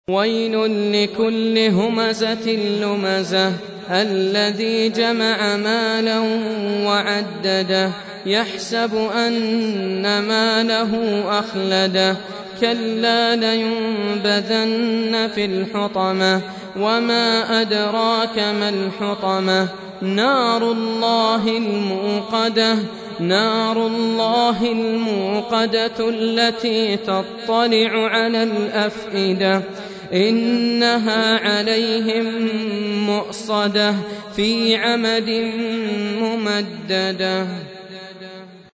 104. Surah Al-Humazah سورة الهمزة Audio Quran Tarteel Recitation
Surah Repeating تكرار السورة Download Surah حمّل السورة Reciting Murattalah Audio for 104. Surah Al-Humazah سورة الهمزة N.B *Surah Includes Al-Basmalah Reciters Sequents تتابع التلاوات Reciters Repeats تكرار التلاوات